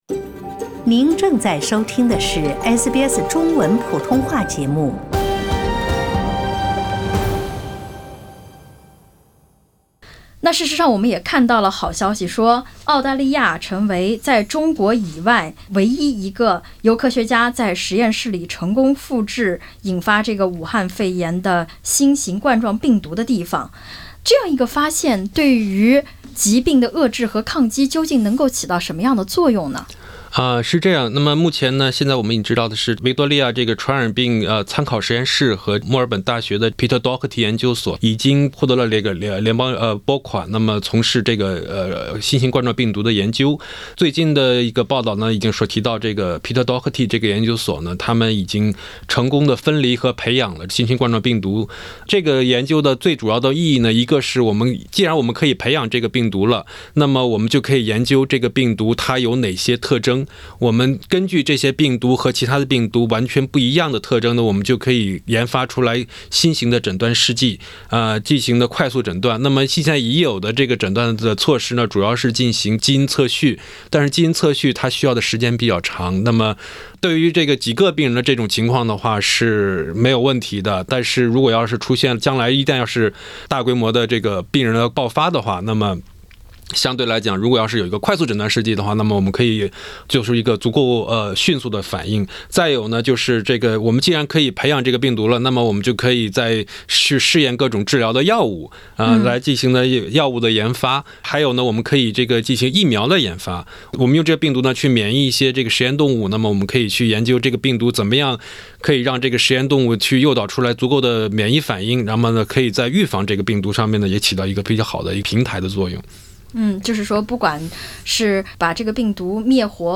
传染病专家专访：澳成功复制冠状病毒，疫苗研制从此开始